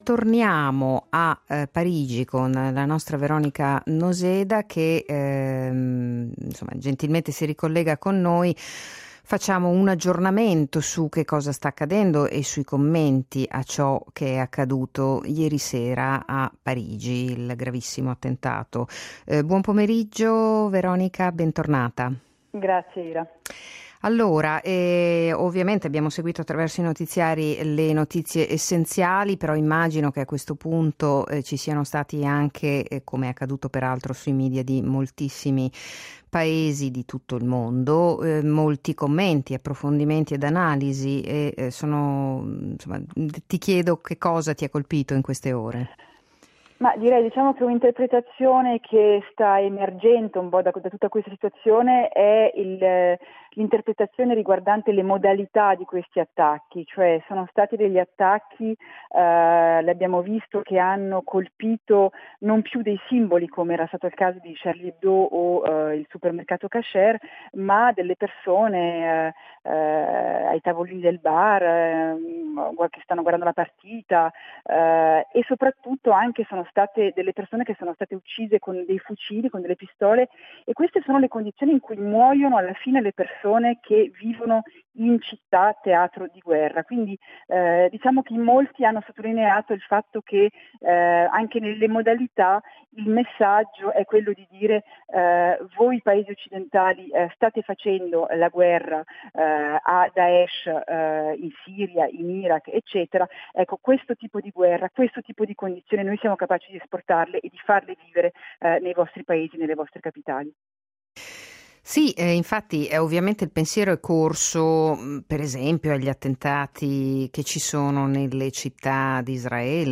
Corrispondenza da Parigi